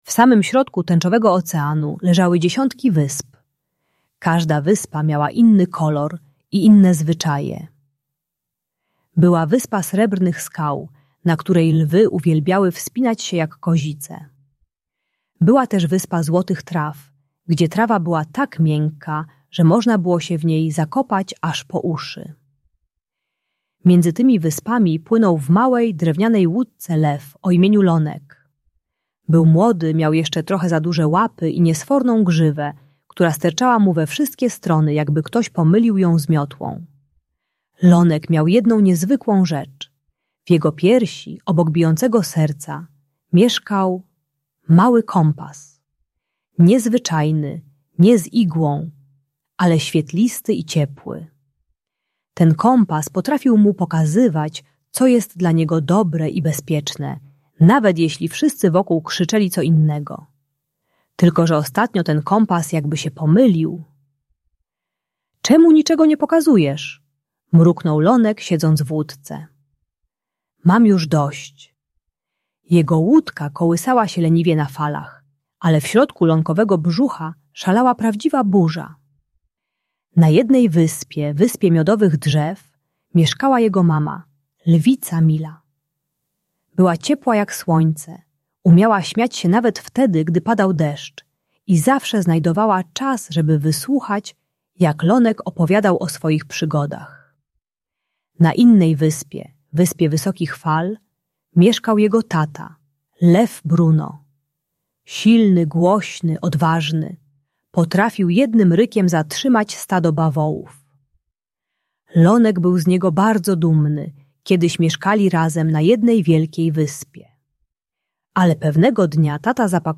Audiobook dla dzieci o rozwodzie rodziców dla dzieci 5-8 lat. Ta bajka terapeutyczna dla dziecka które przeżywa rozstanie rodziców pomaga zrozumieć, że można kochać oboje rodziców bez wybierania stron.